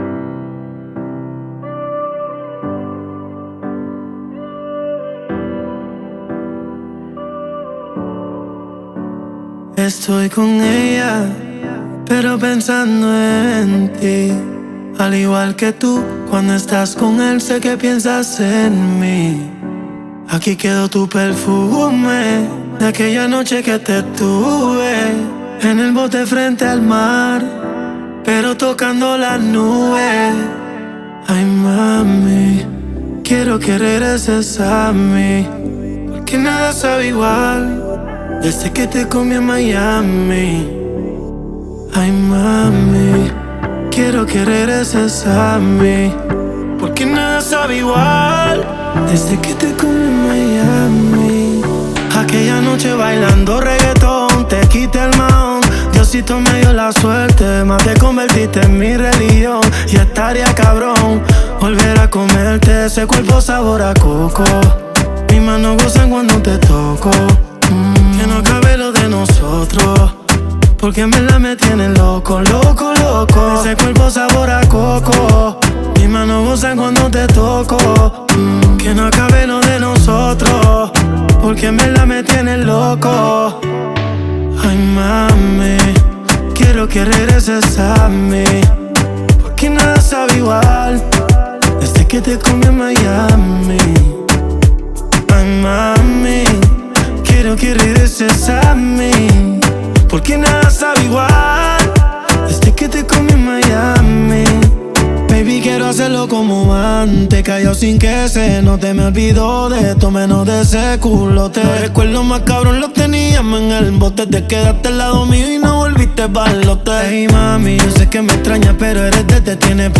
зажигательная реггетон-песня